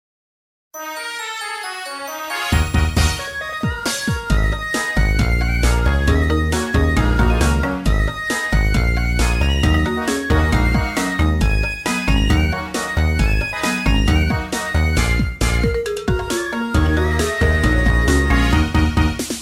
funky, jazz-inspired melody
Jazz